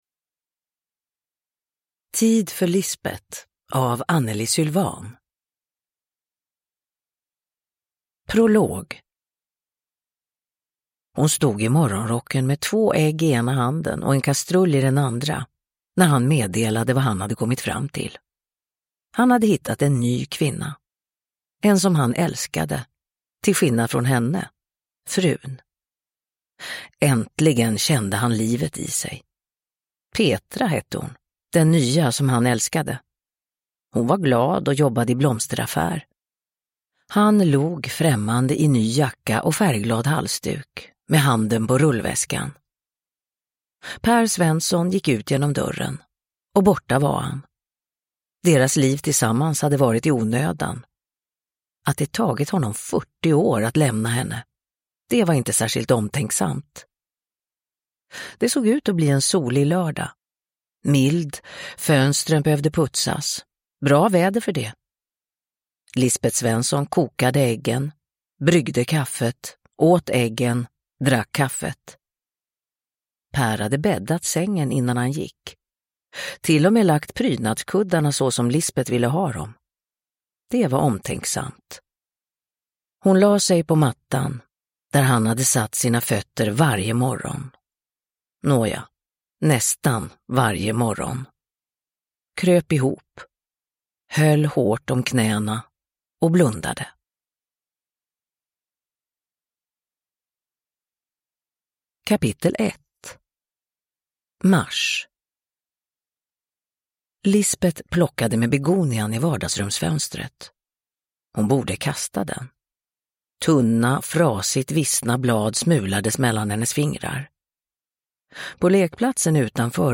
Tid för Lisbeth – Ljudbok
Uppläsare: Marie Richardson